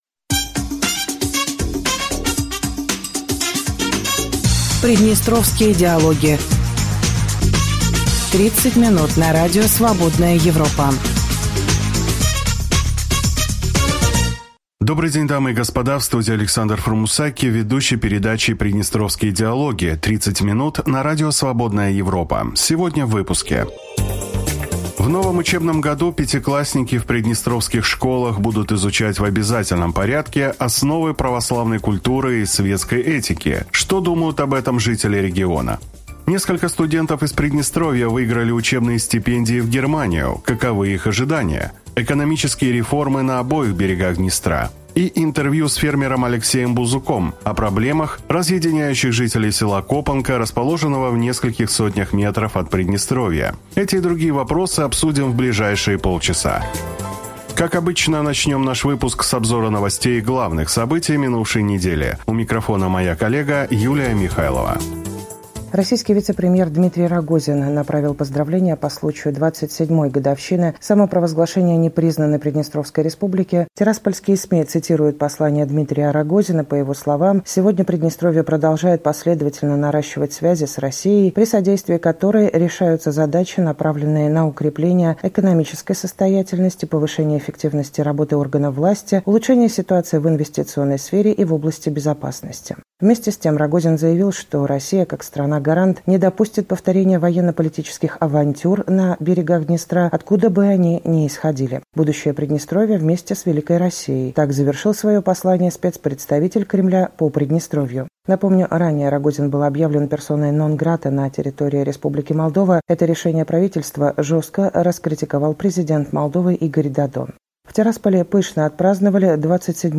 Дорогие радиослушатели, добрый день.